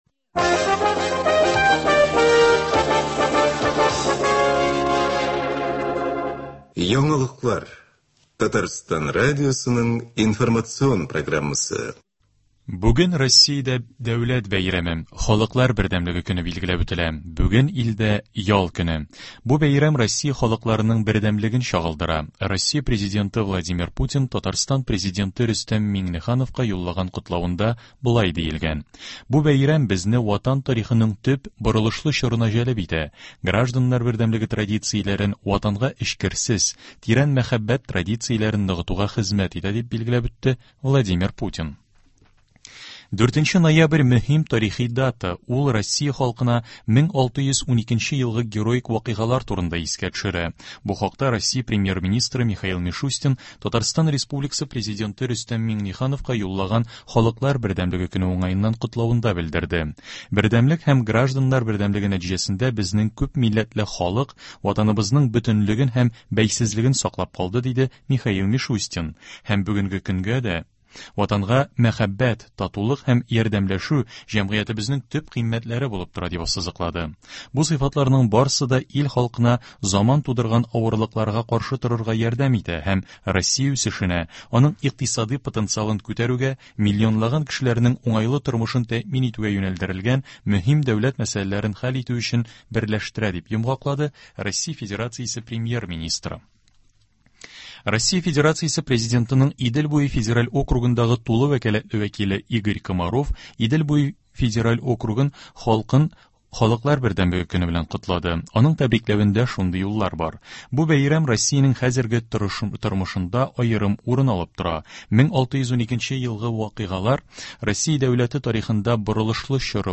Яңалыклар (04.11.20)